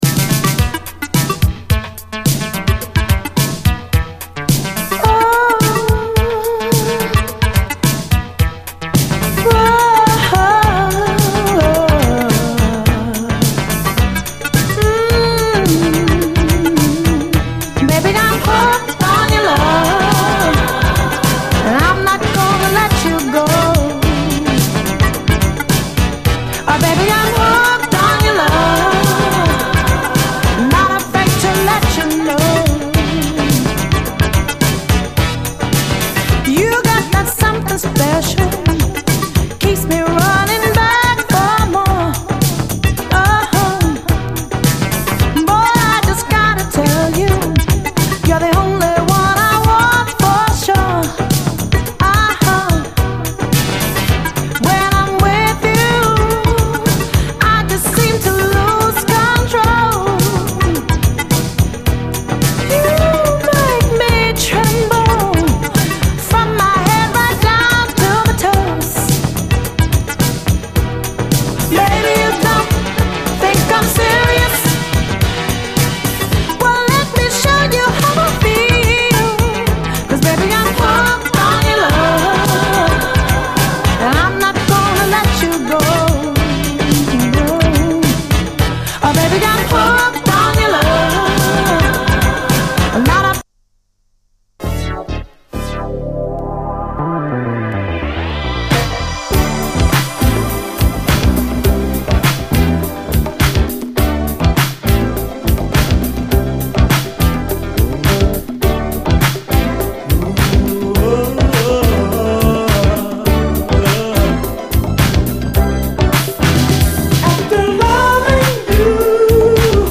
SOUL, 70's～ SOUL, DISCO
こちらもレアな80’Sシンセ・モダン・ソウルをカップリングしたUK盤12インチ！
細かいシンセ・アレンジと流麗なメロディーのNYサウンド・チューン！
アーバン＆アダルトなムードの80’Sシンセ・モダン・ソウル！